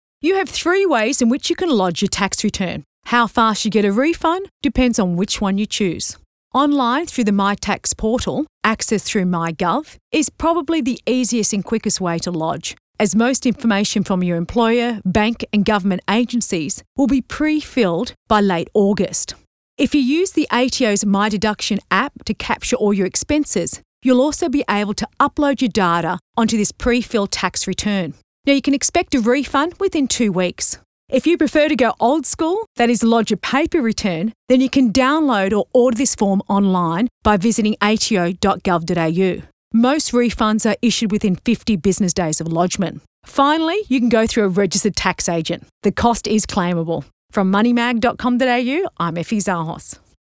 Each segment runs exactly 50 seconds, so you can add your own local sponsor credit to make it a neat 60 seconds.